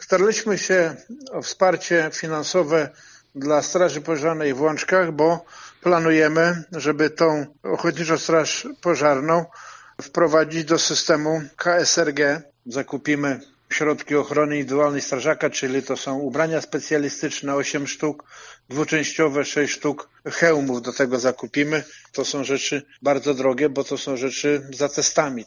O potrzebach jednostki mówi Wójt gminy, Grzegorz Fabiszewski: